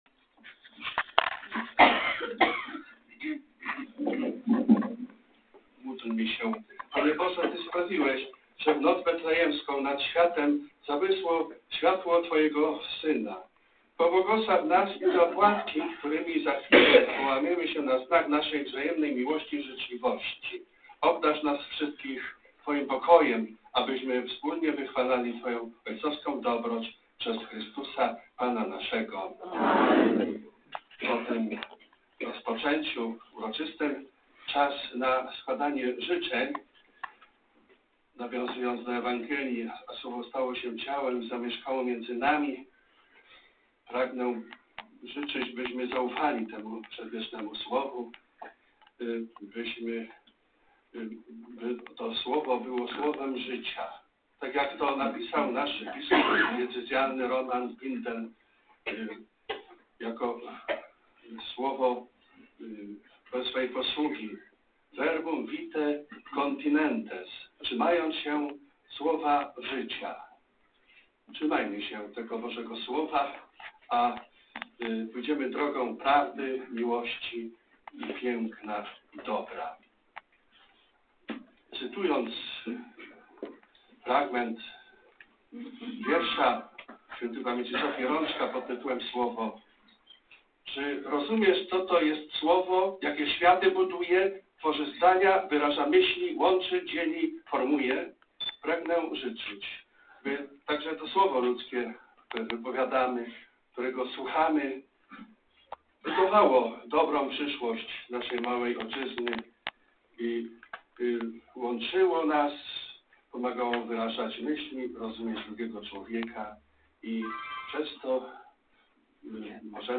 Spotkanie opłatkowe
Życzenia wraz z błogosławieństwem